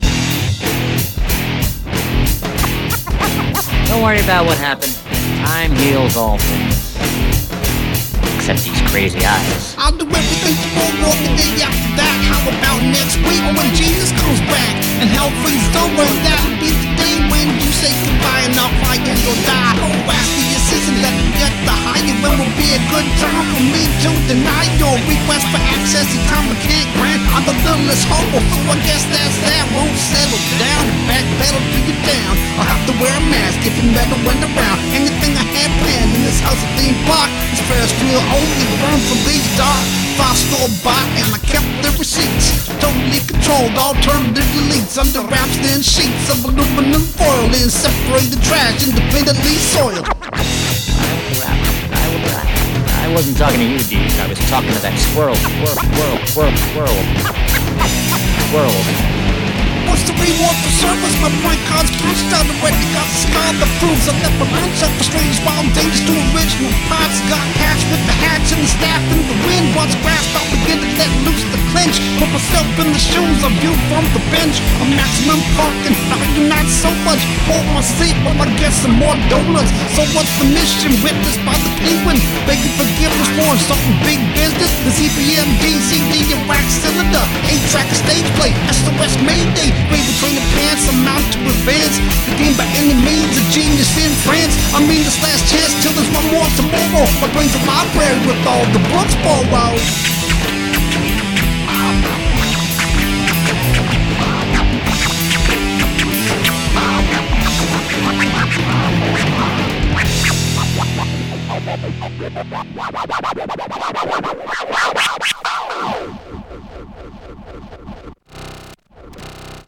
Don’t expect polish.
It’s loud.